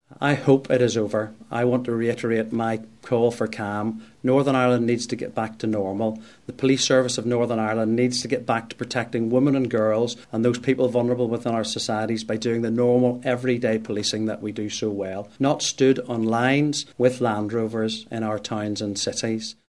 PSNI Assistant Chief Constable Ryan Henderson says normality needs to be restored.